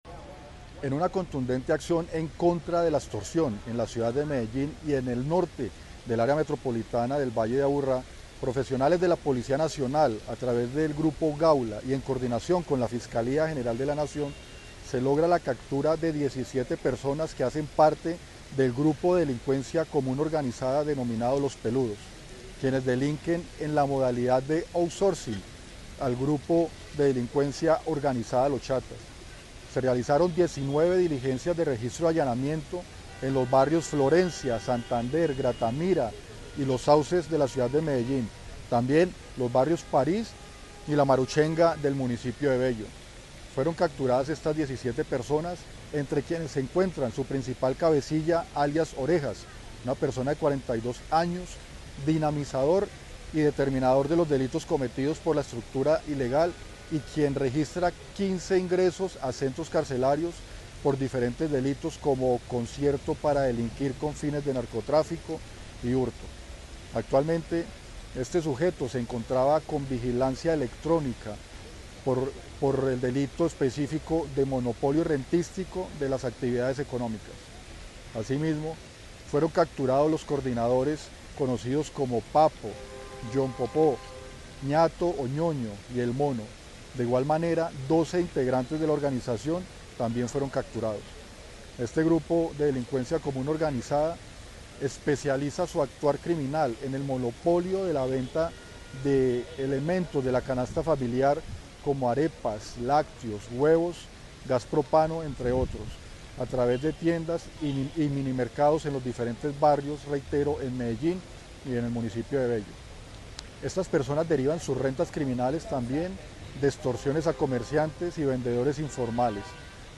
Palabras de Brigadier General William Castaño Ramos, Comandante Policía Metropolitana del Valle de Aburrá